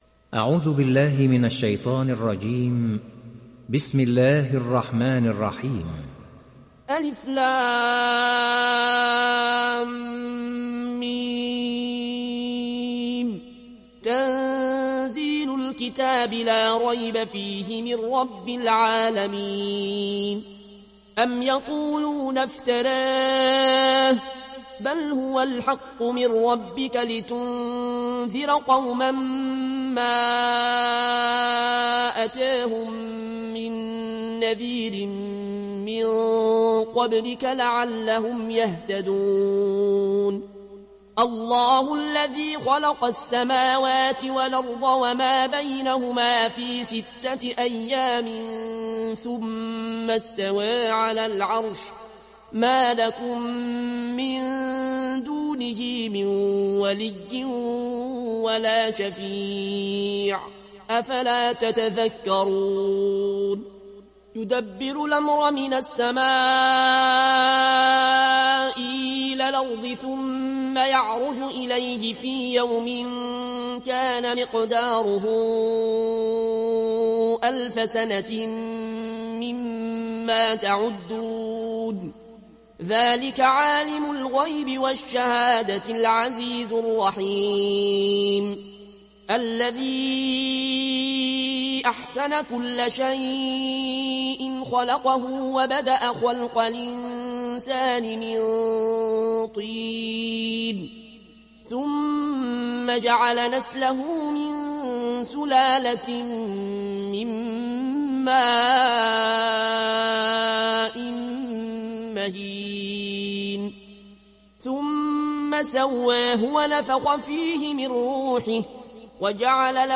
(Riwayat Warsh)